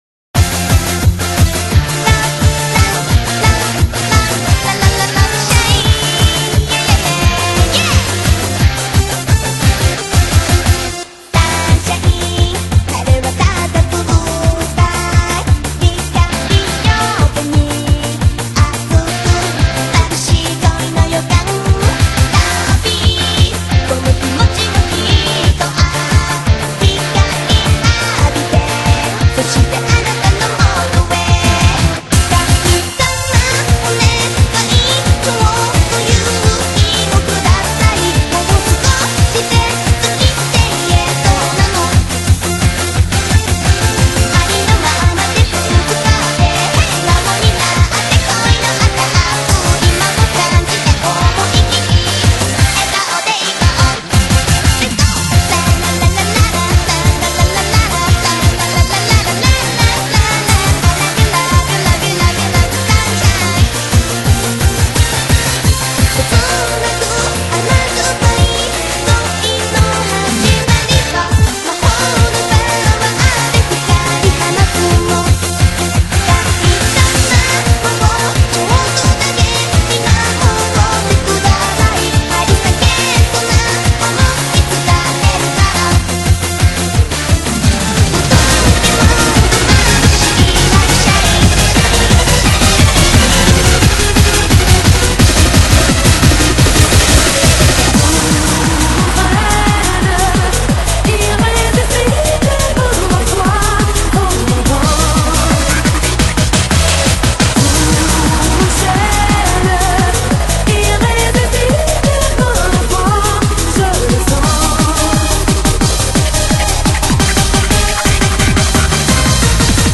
BPM170-180
Audio QualityPerfect (High Quality)
upbeat, happy-hardcore